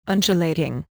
Click here to hear the pronunciation of undulating.